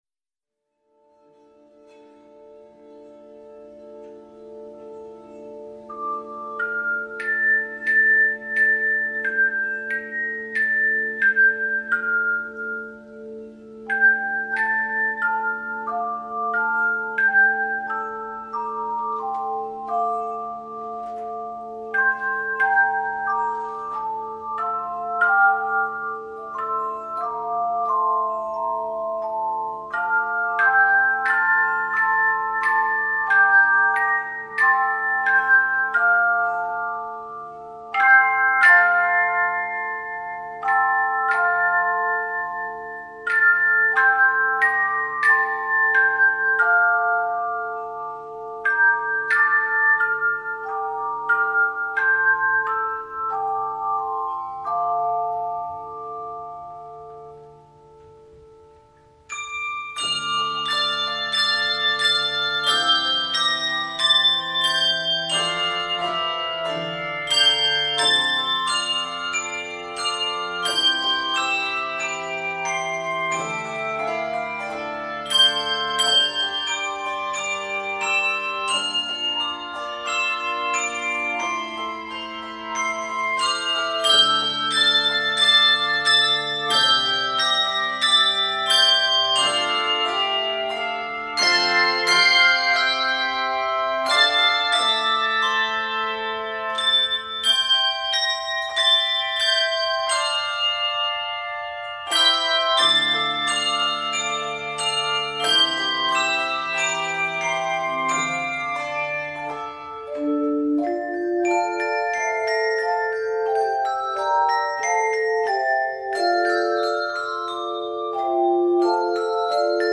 meditative setting